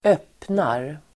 Uttal: [²'öp:nar]